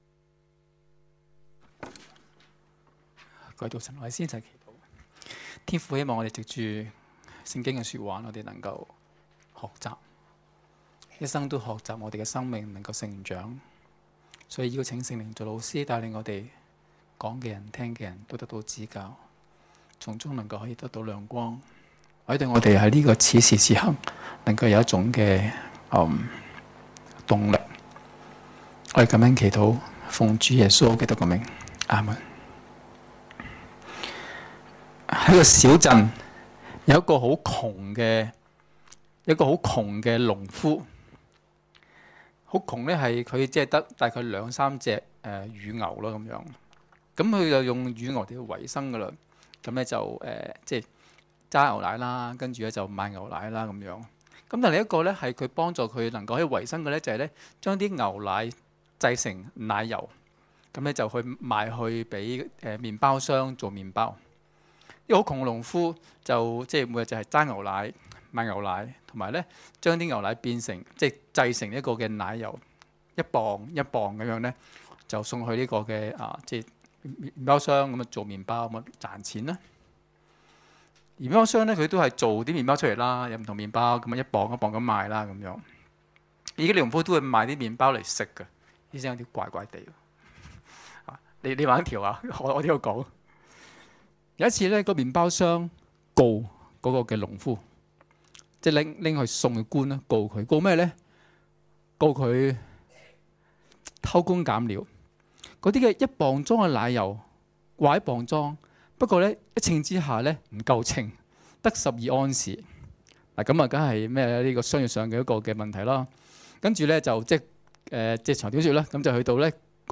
2016年10月8日及9日崇拜講道